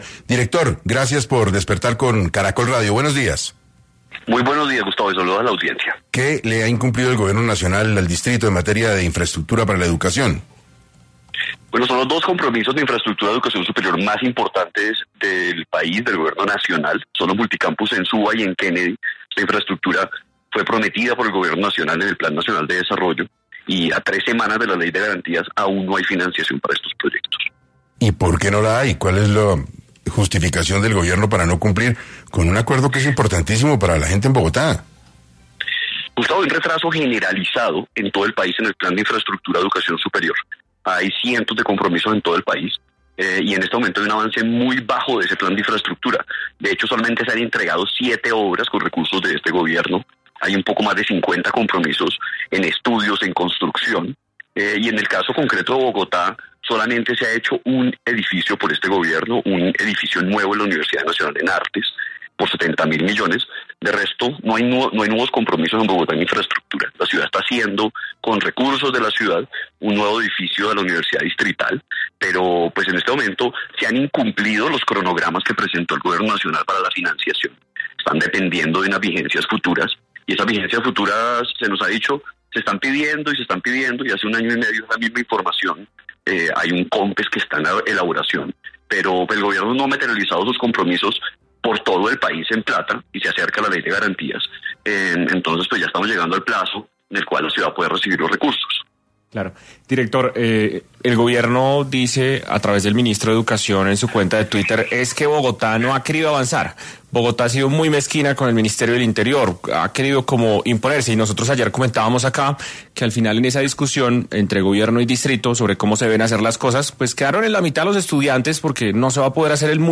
En entrevista con 6AM el director Víctor Saavedra, habló de los incumplimientos del Gobierno al Distrito en materia de infraestructura en educación: “En este momento hay un avance muy bajo del plan de infraestructura, hay un poco más de 50 compromisos en estudios y en construcción, en el caso concreto de Bogotá solo se ha construido un edificio en la Universidad Nacional en artes por $70 mil millones, por ahora no hay nuevas construcciones, en este momento se ha incumplido los cronogramas que había indicado el Gobierno”.